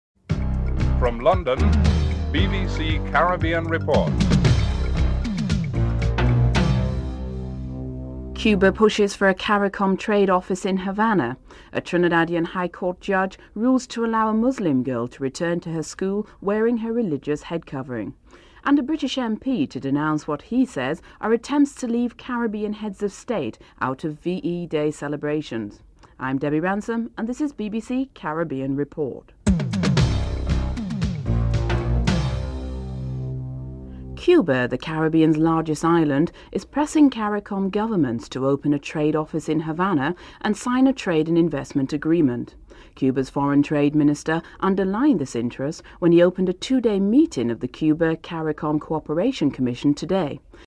4. Interview with Edwin Carrington on CARICOM's reluctance to take up the offer and whether the US embargo contributed towards this decision (02:13-04:24)